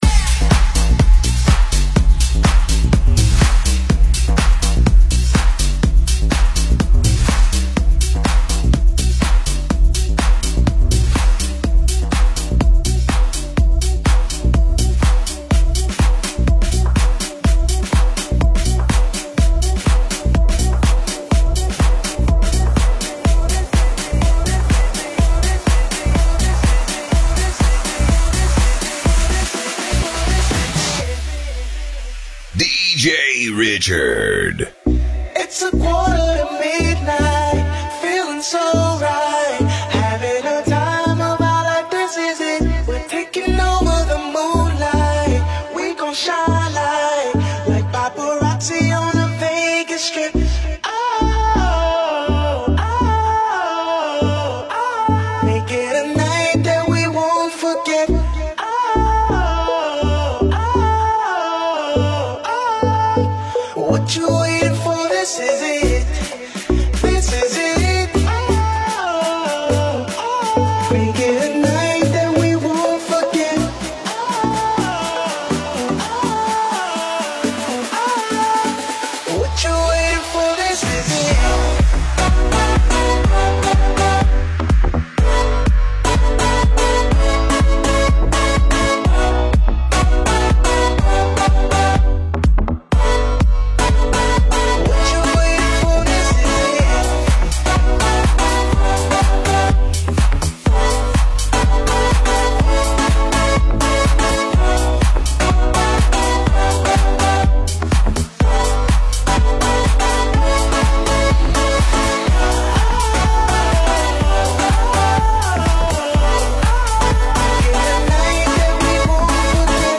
mixado